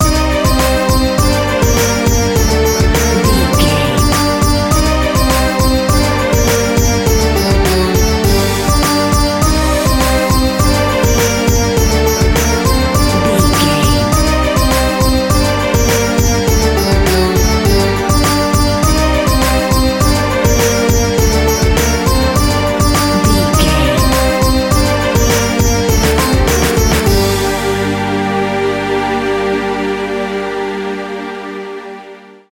Aeolian/Minor
percussion